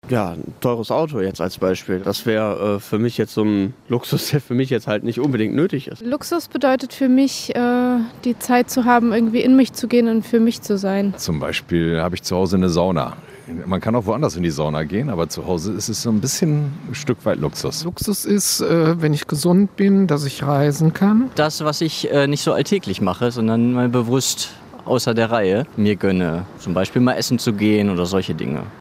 Umfrage Luxus